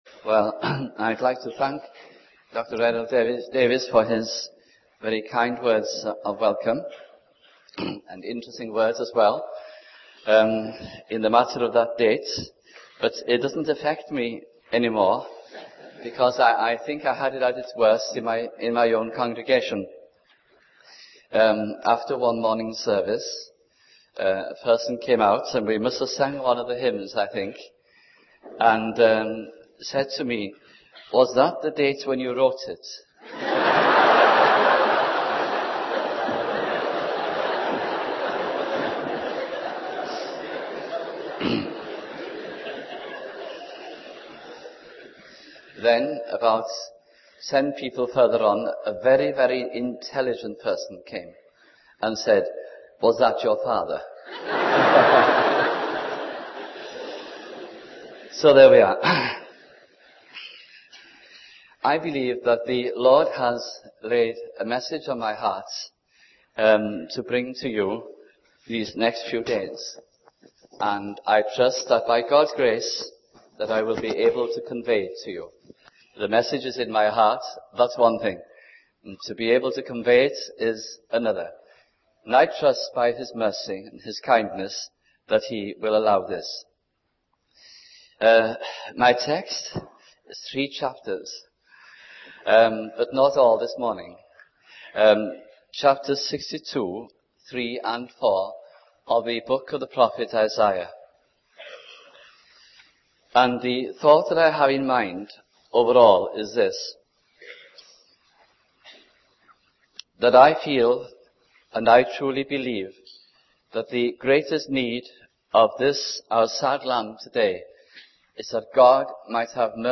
» Revival - Aberystwyth Conference 1984 » series of four messages based on Isaiah 64 » the book ' Turn of the Tide' was based on these messages